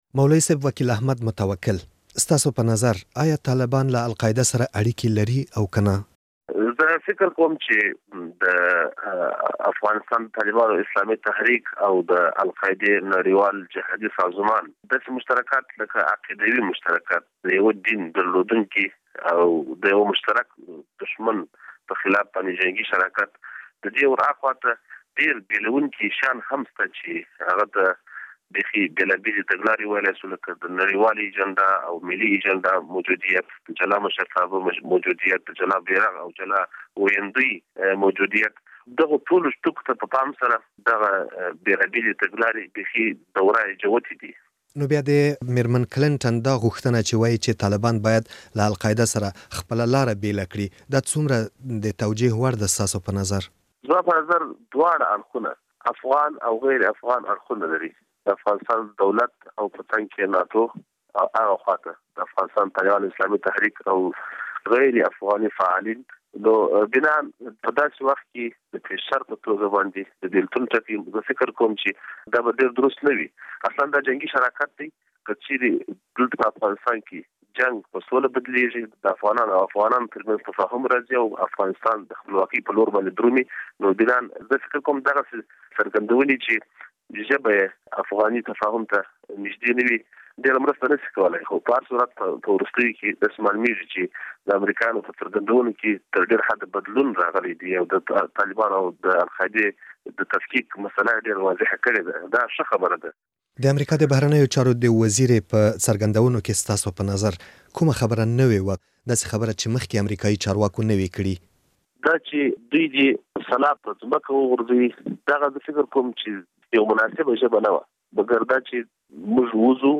له وکیل احمد متوکل سره مرکه له دې ځا یه واورئ